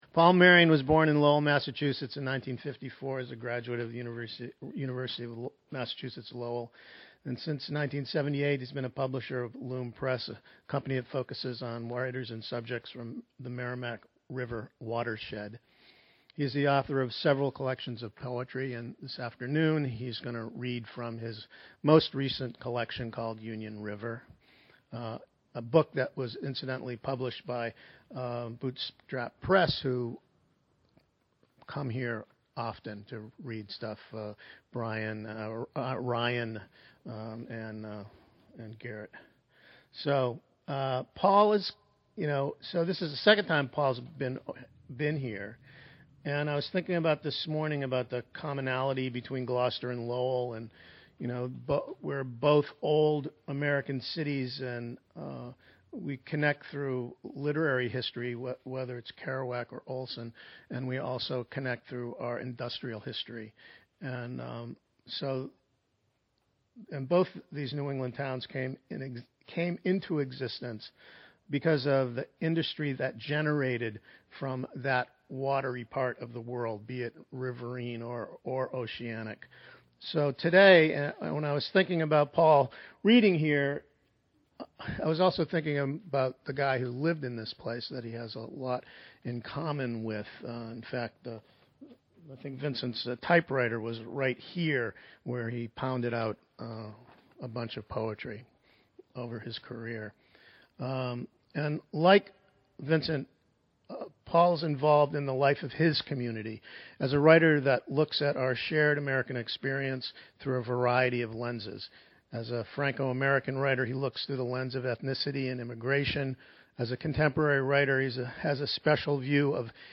Reading Podcast
read some of their poetry at the GWC…